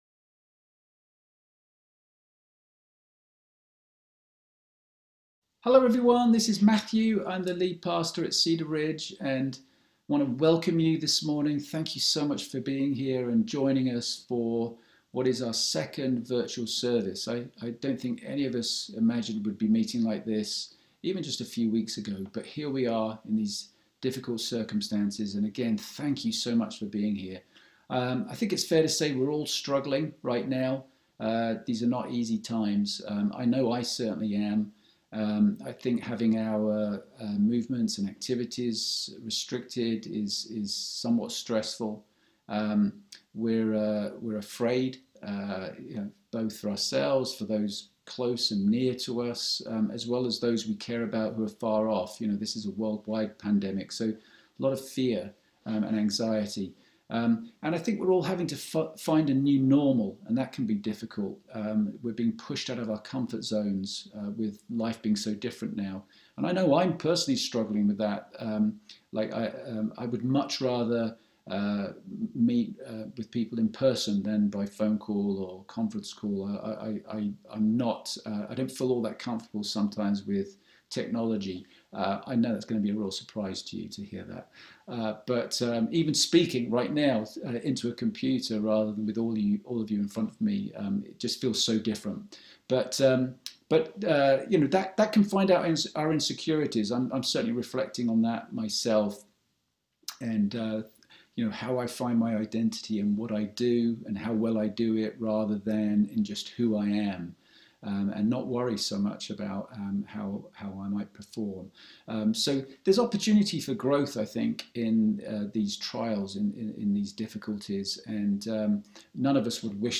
A message from the series "Next Steps." We look at the invitation to struggle with doubt, be real (more than right), "be being filled" with the Spirit, and re-engage with our church community.